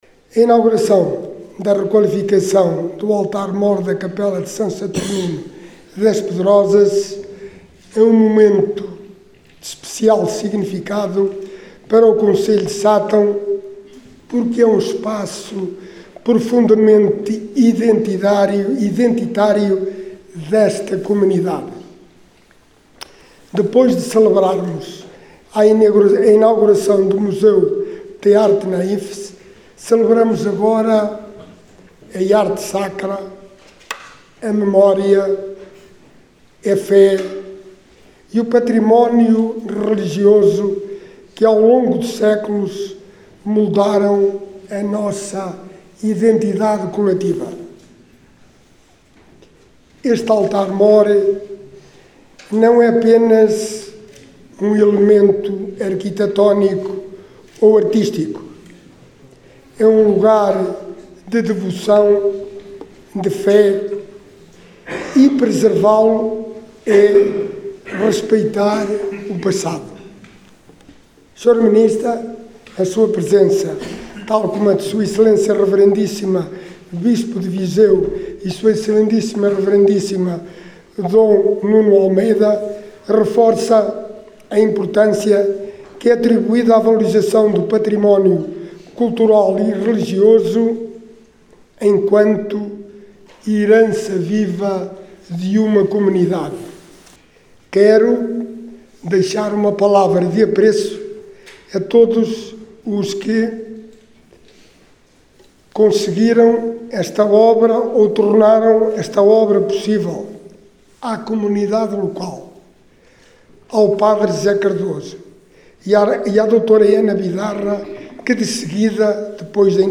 Nesta segunda-feira, 19 de janeiro, na localidade de Pedrosas, no concelho de Sátão, foram inauguradas as obras de restauro do Altar Mor da Capela de São Saturnino, numa cerimónia que contou com a presença de Alexandre Vaz, Presidente da Câmara Municipal de Sátão, Margarida Balseiro Lopes, Ministra da Cultura, Juventude e Desporto e de D. António Luciano, Bispo de Viseu, entre outras entidades convidadas.
Alexandre Vaz, Presidente do Município de Sátão, referiu que esta inauguração é um momento de especial significado para as Pedrosas e concelho de Sátão “este é um lugar de devoção e fé e preservá-lo é respeitar o passado…”.